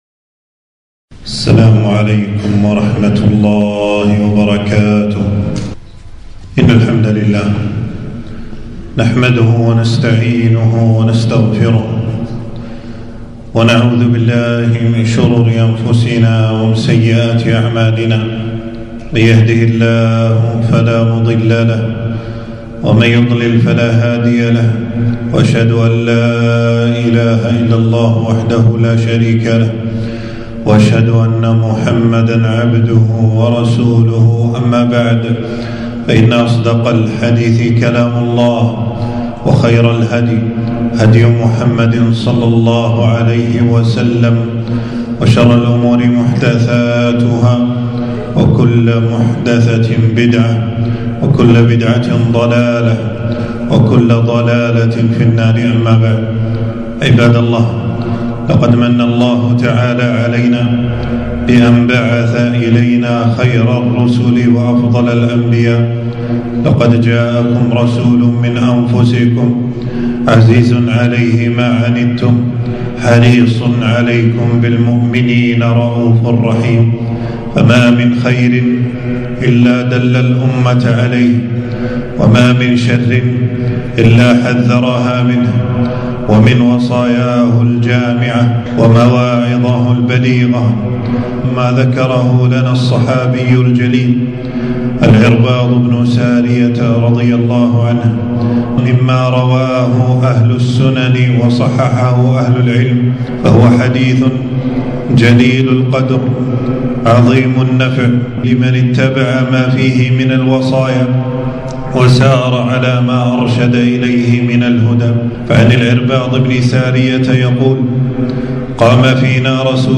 خطبة - وصية رسول الله صلى الله عليه لأصحابه - دروس الكويت